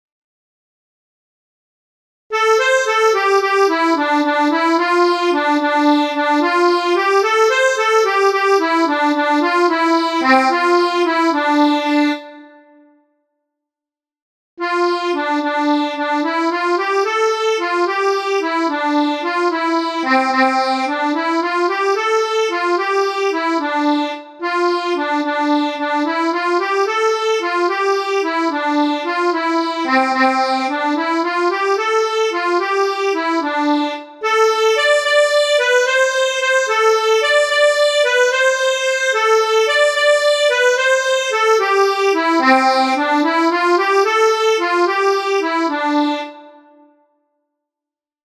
The_rocky_Road_to_Dublin_solo_melodia2.mp3